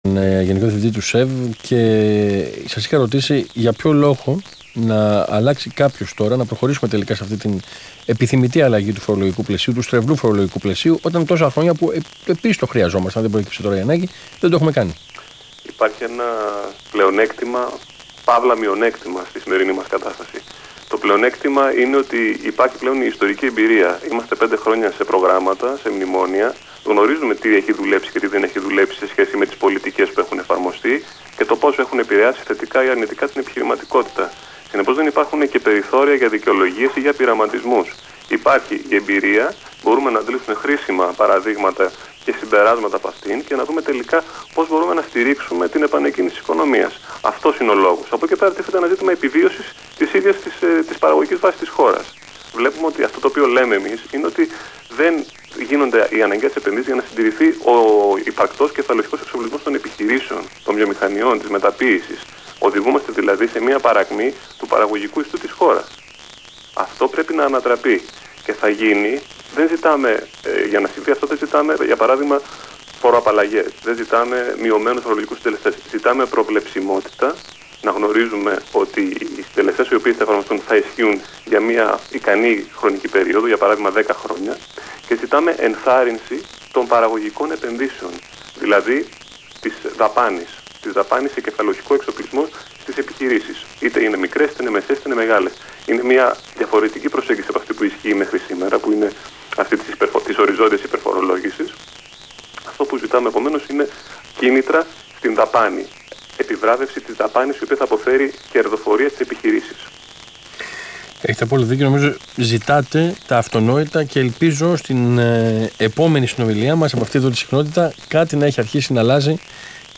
Συνέντευξη του Γενικού Διευθυντή του ΣΕΒ, κ. Άκη Σκέρτσου στον Ρ/Σ Αθήνα 9.84, 30/12/15